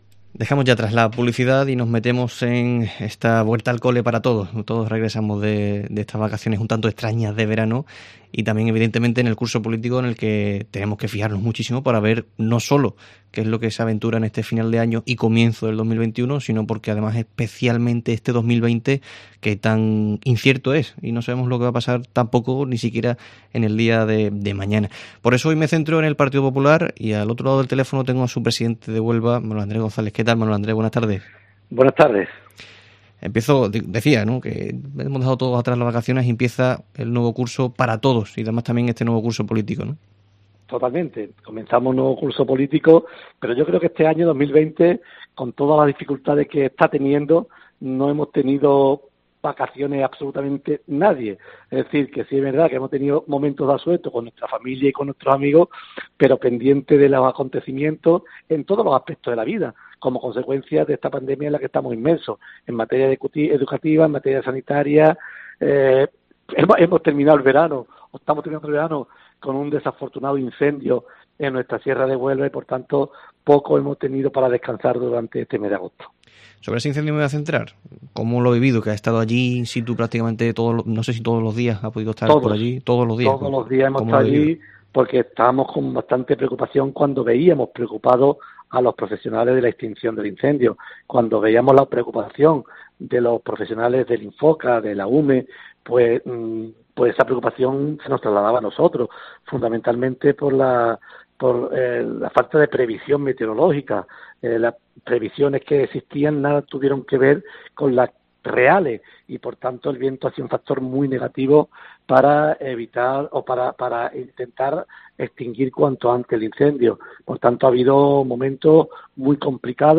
Diferentes cuestiones de actualidad hemos abordado con Manuel Andrés González, presidente de los Populares de Huelva, en el sprint final del Herrera en COPE Huelva de este viernes.